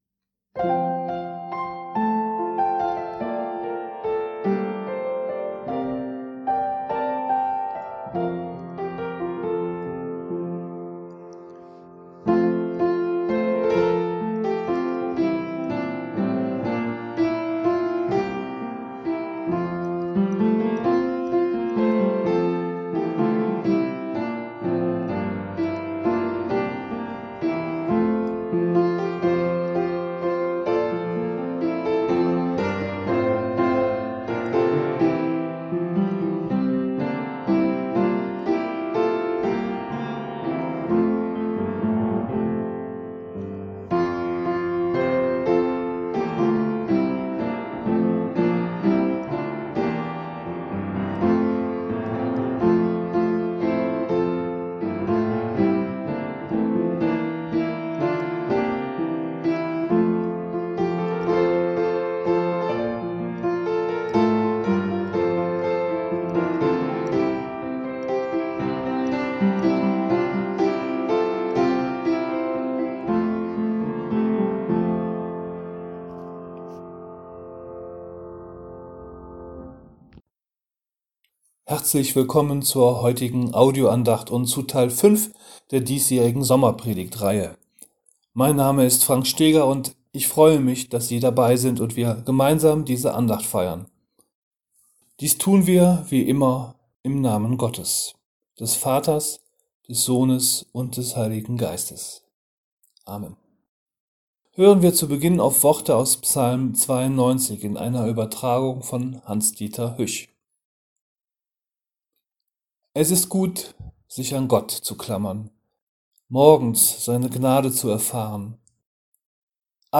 Audio-Andacht : Ein störrischer Prophet und sein Psalm - Neues